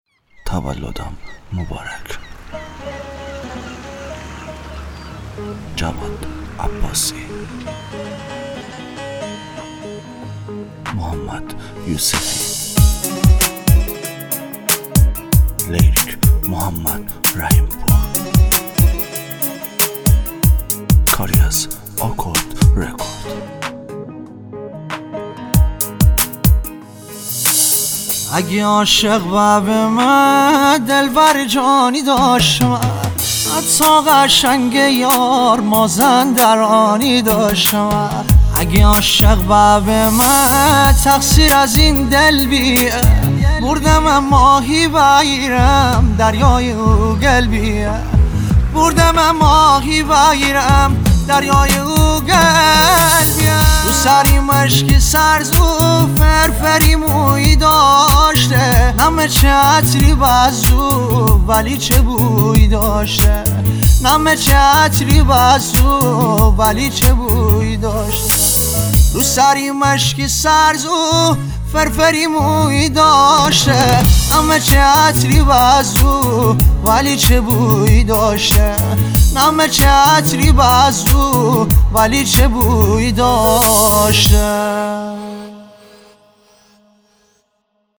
آهنگ مازندرانی